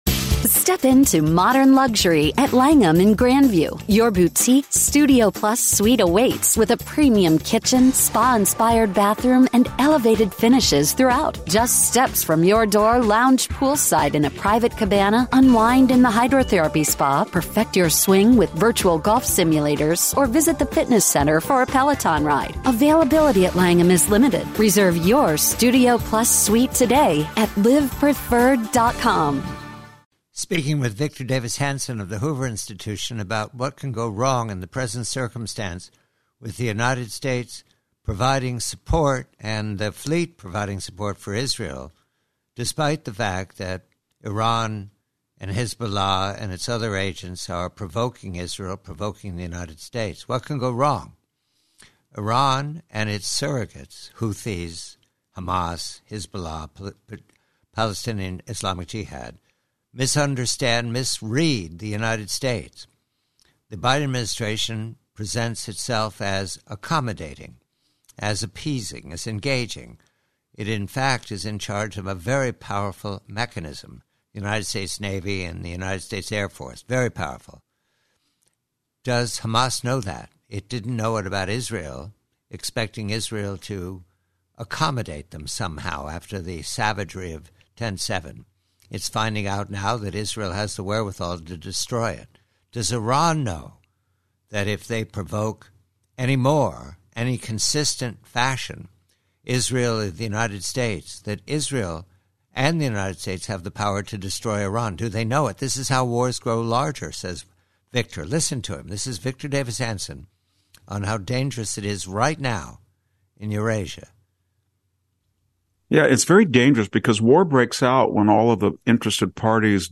PREVIEW: From a longer conversation with Victor Davis Hanson of Hoover on the dangers of a wider war in Eurasia because Tehran is misreading Washington and Jerusalem - as did Hamas before the IDF counterattacked to end Hamas.